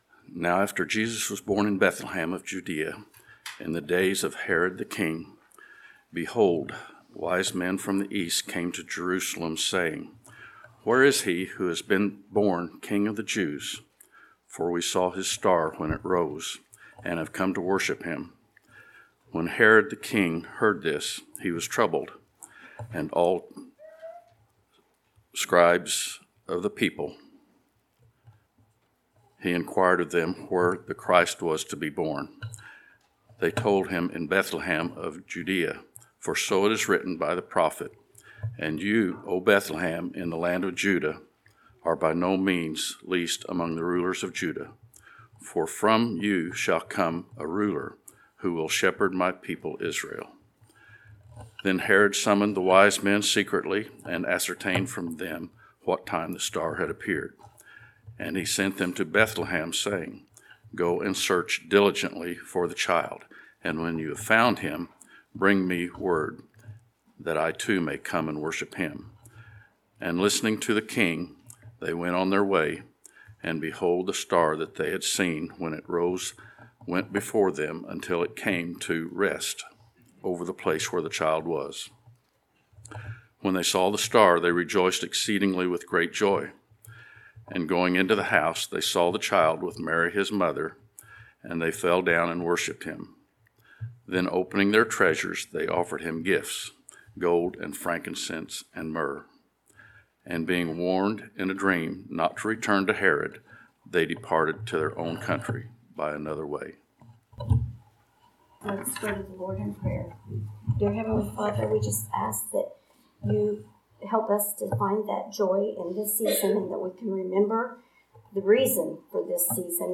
Advent 2024 Passage: Matthew 2:1-12 Service Type: Sunday Morning Related Topics